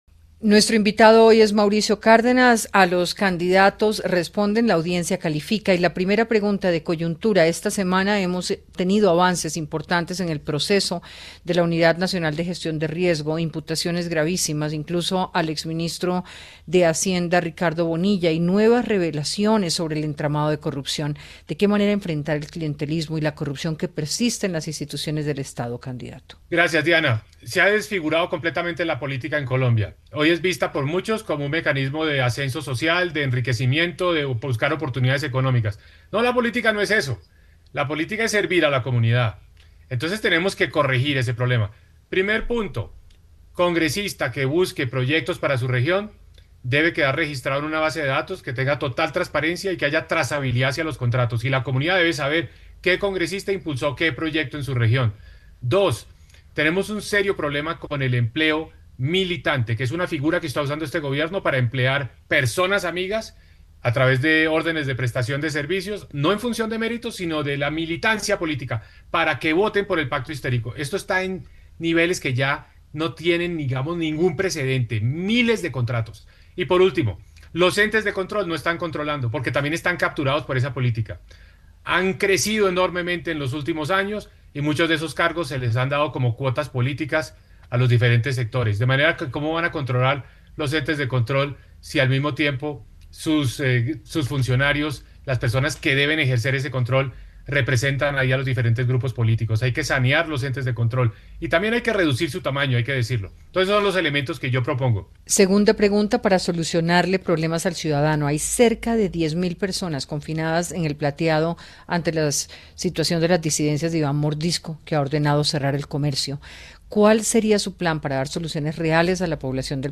El precandidato presidencial habló en Hora20 sobre el panorama de corrupción en el país y la compleja situación de orden público en El Plateado.